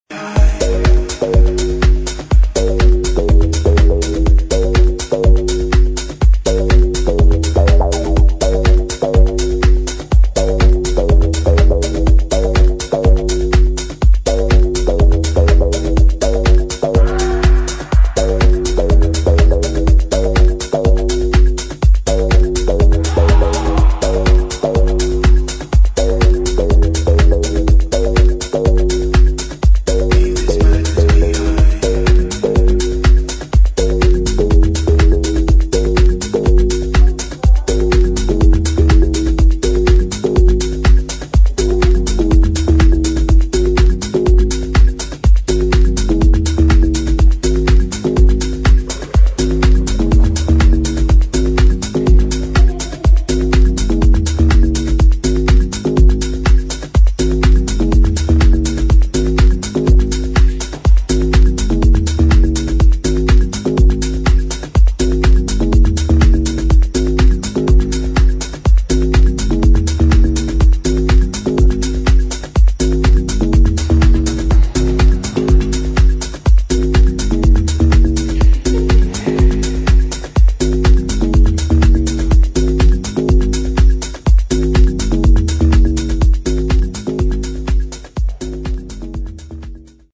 [ HOUSE | DUB | ELECTRONIC ]